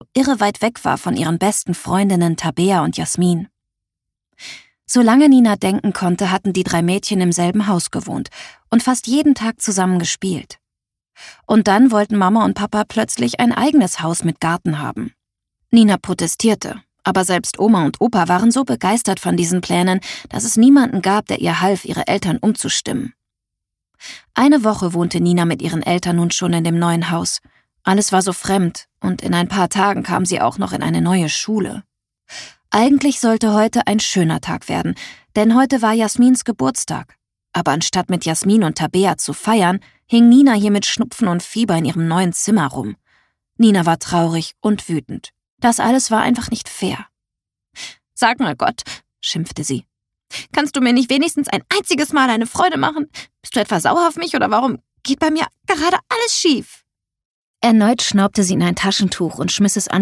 Über Hoffnung, Traurigsein und Trost - Hörbuch